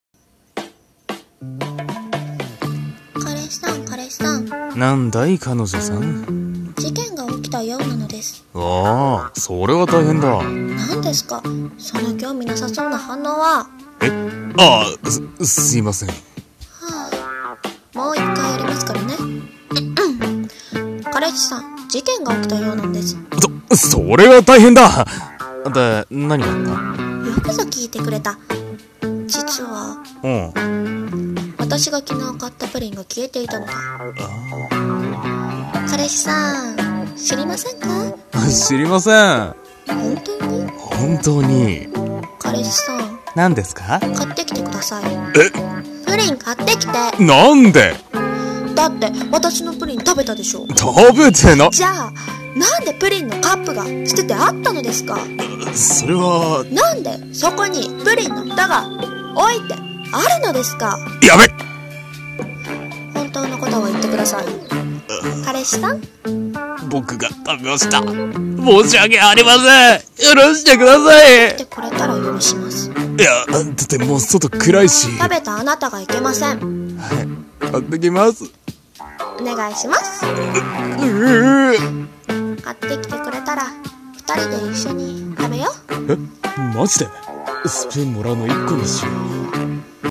【声劇台本 】プリン